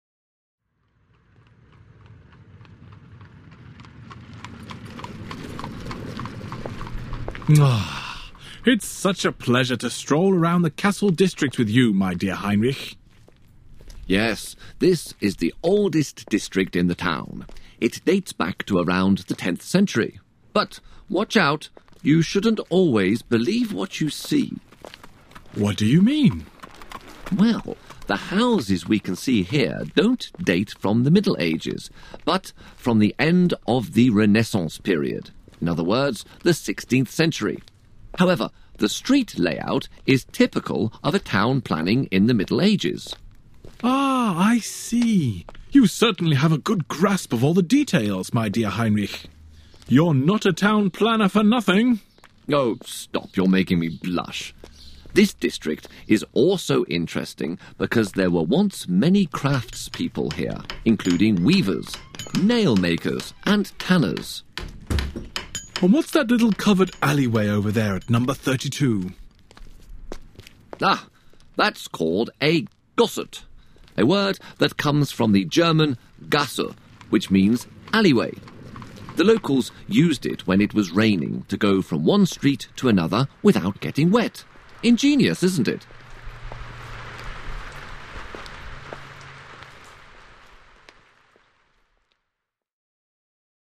Il s’agit d’une discussion entre Frédéric 1er, duc de Wurtemberg et son architecte, Heinrich Schickhardt.